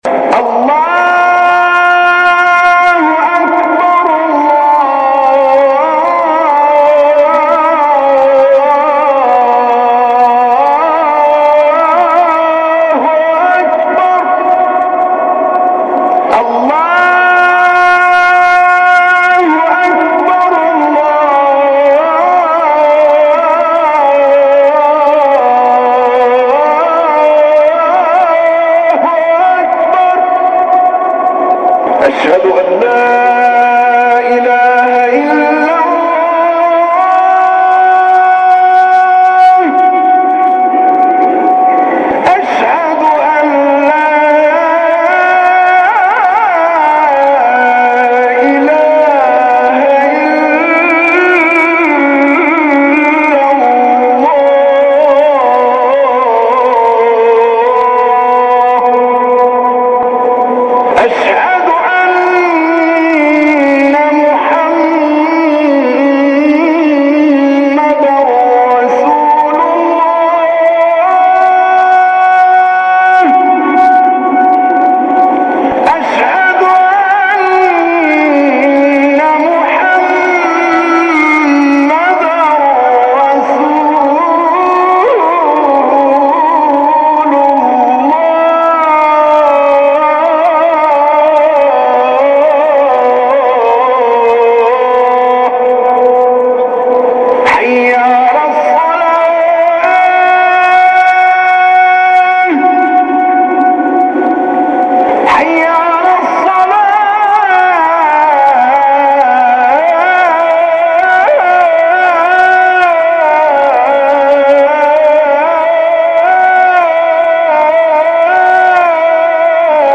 أذان